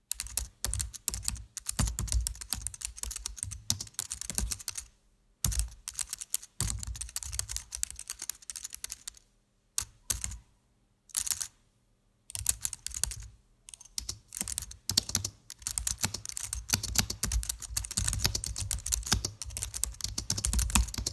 Apple Mac Keyboad typing close up
apple click clicking close computer desktop fast fingers sound effect free sound royalty free Sound Effects